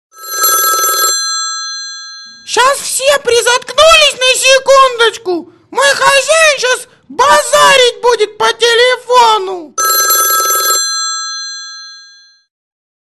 Звонок - Фраза - Звонок 128 (моно)